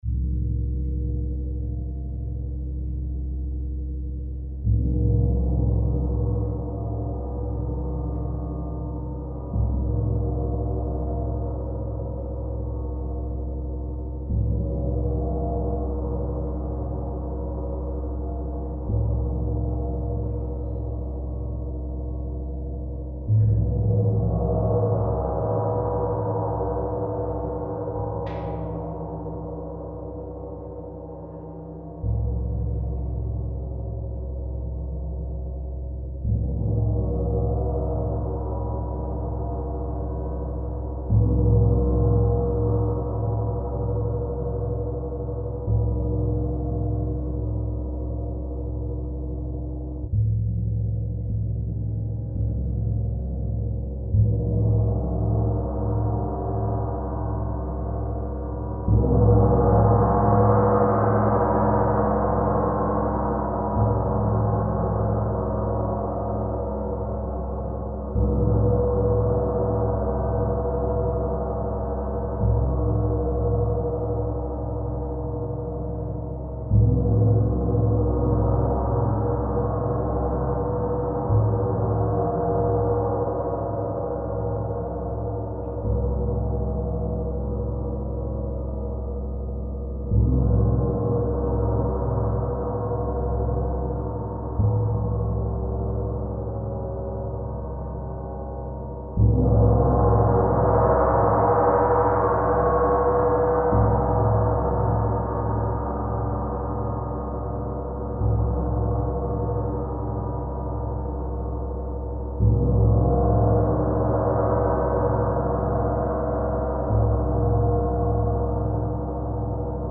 Esta grabación es la real del Gong disponible
Gong Sinfónico 50cm